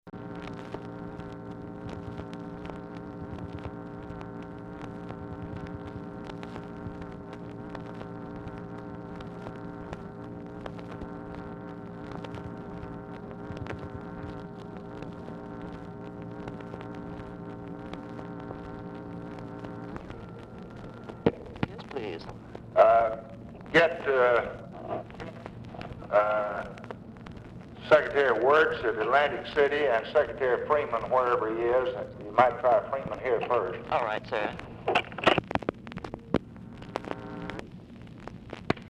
Telephone conversation # 5145, sound recording, LBJ and TELEPHONE OPERATOR, 8/24/1964, time unknown | Discover LBJ
Format Dictation belt